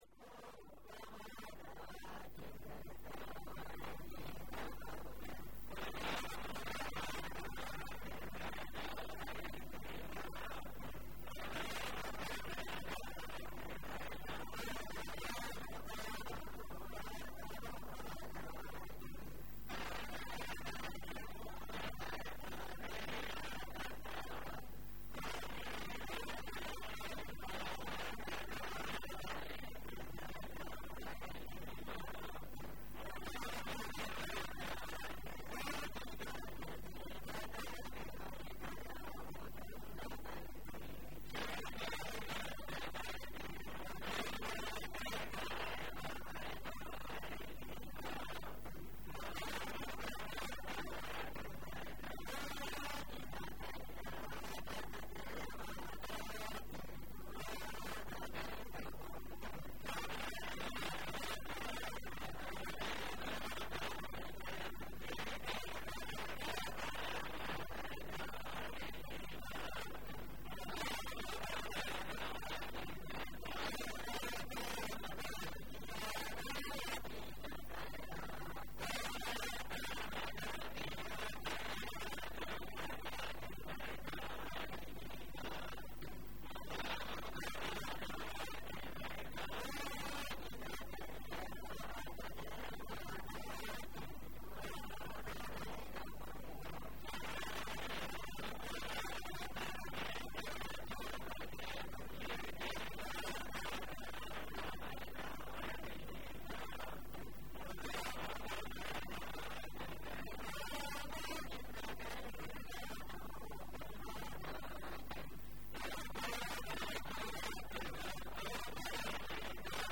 Wakokin Gargajiya